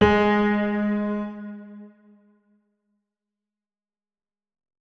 46265b6fcc Divergent / mods / Hideout Furniture / gamedata / sounds / interface / keyboard / piano / notes-32.ogg 55 KiB (Stored with Git LFS) Raw History